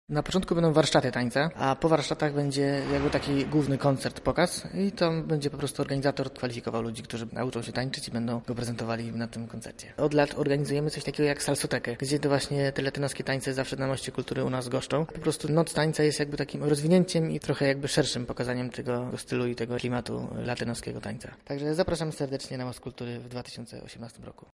noc tańca serwis